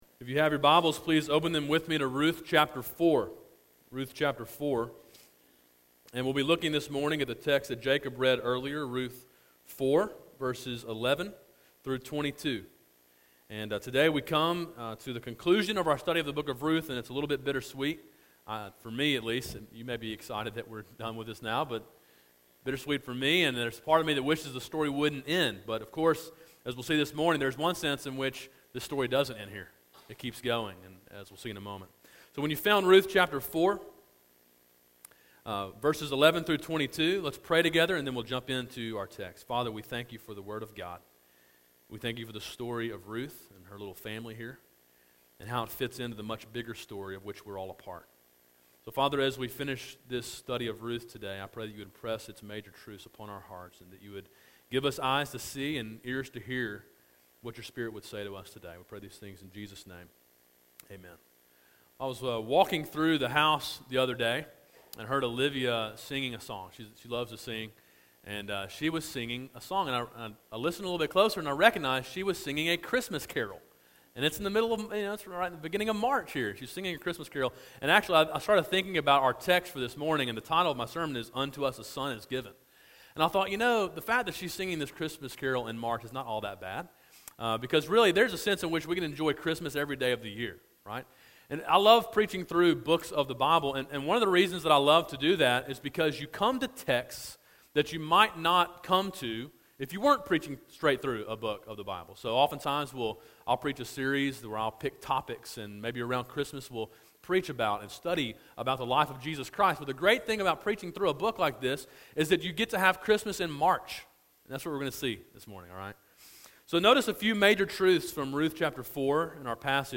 A sermon in a series on the book of Ruth.